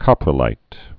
(kŏprə-līt)